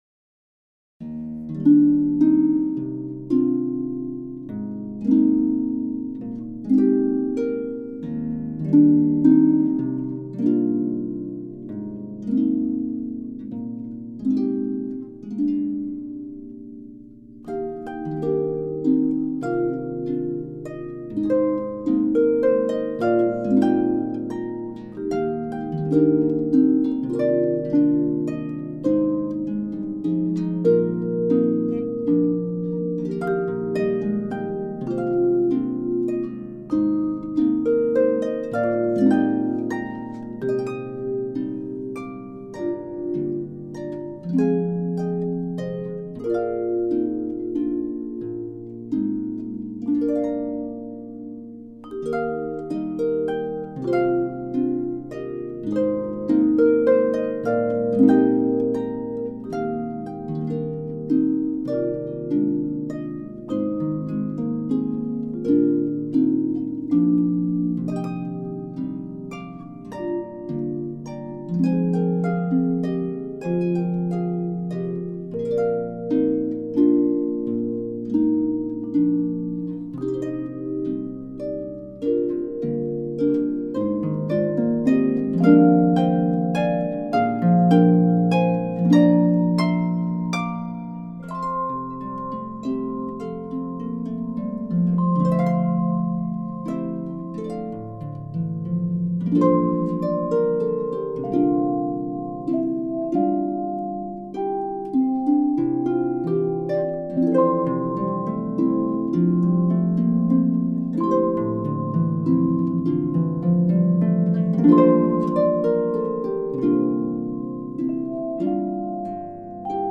Pedal harp version: